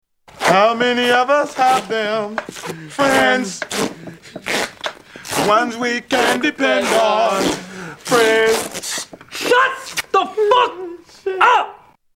Category: Comedians   Right: Personal
Tags: Mike Epps Sounds Mike Epps clips Mike Epps audio Mike Epps Comedian